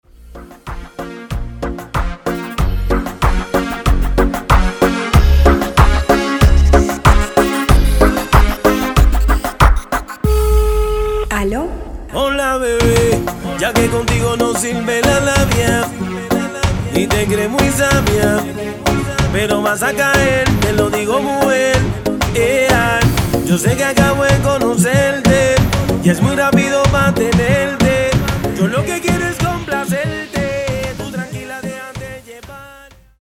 95 / Reggaeton